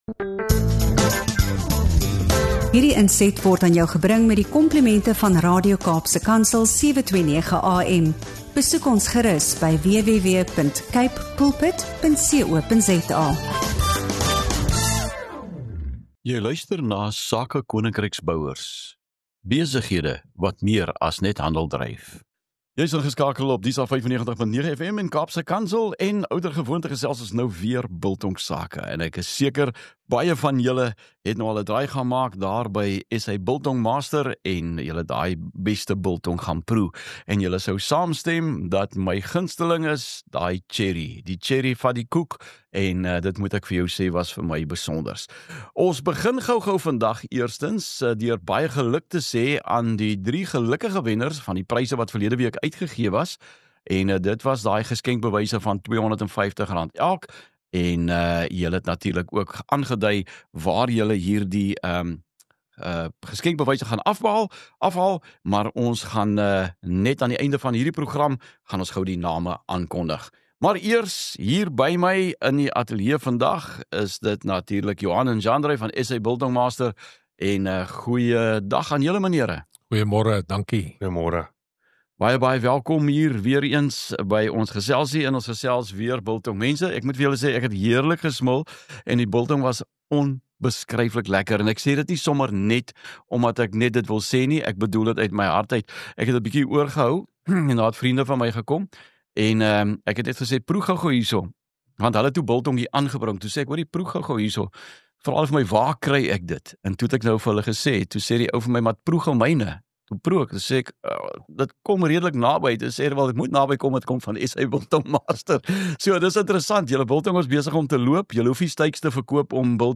In hierdie inspirerende episode van Sake Koninkryksbouers op Radio Kaapse Kansel 729 AM gesels ons oor besighede wat méér as net handel dryf.